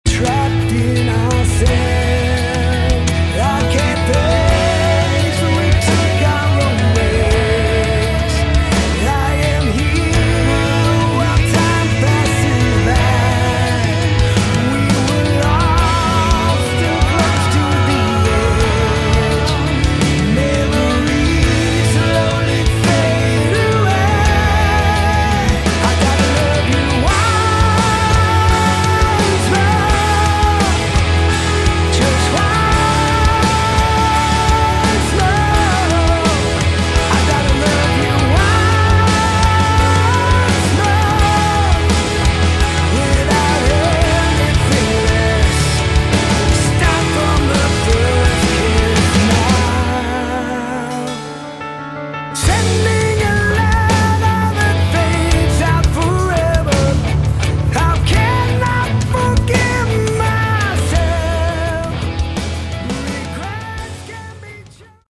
Category: Modern Hard Rock
lead vocals, drums
guitars
bass